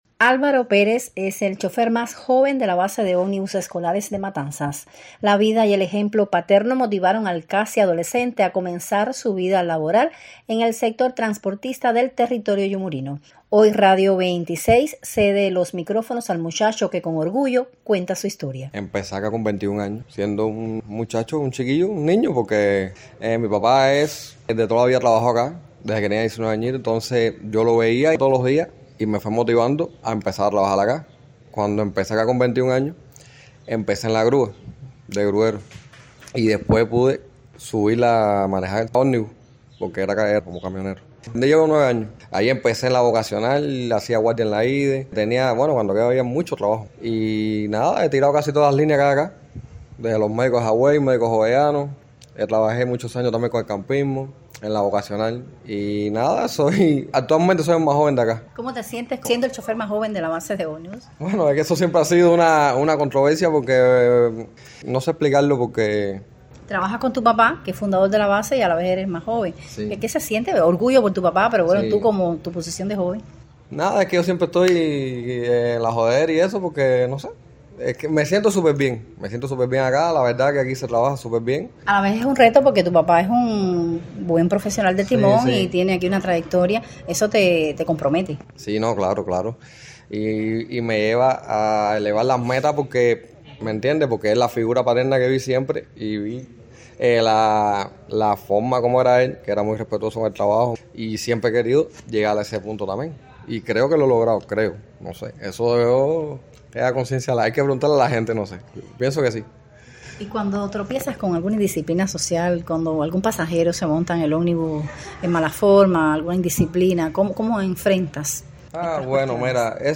Hoy Radio 26 cede los micrófonos al muchacho que con orgullo cuenta su historia.